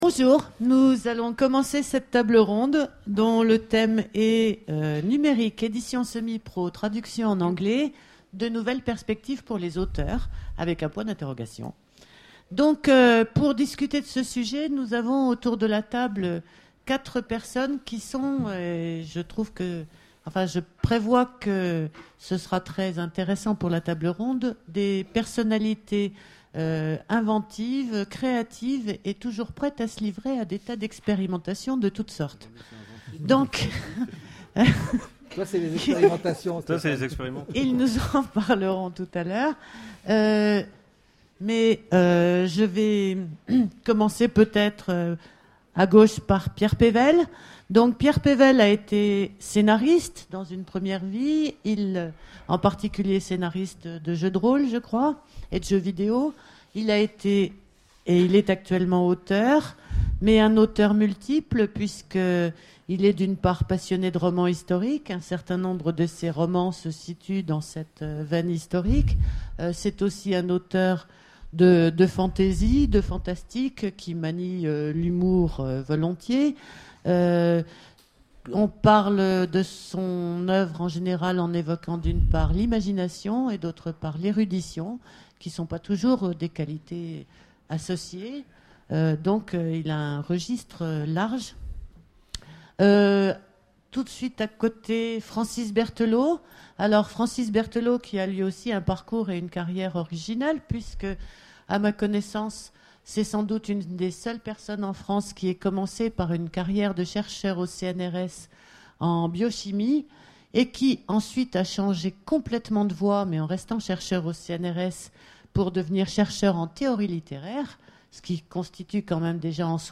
Imaginales 2013 : Conférence Numérique, édition semi-pro, traductions en anglais...